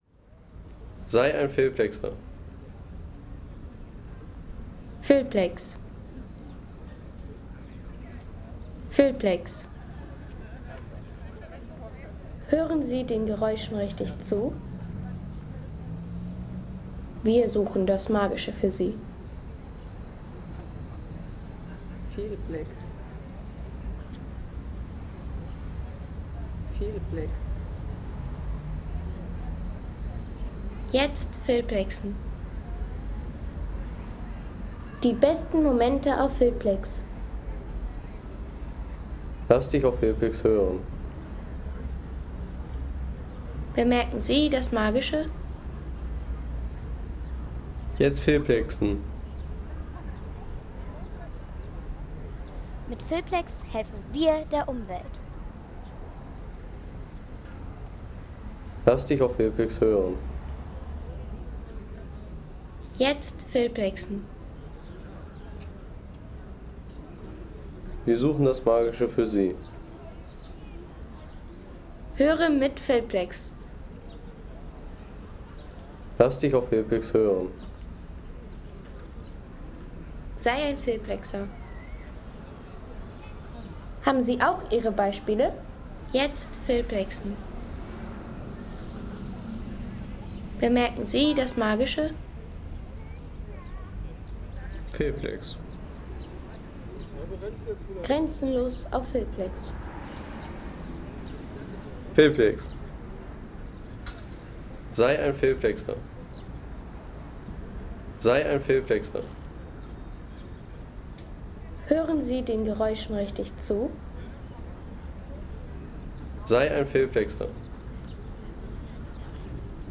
Marktplatz – Innsbruck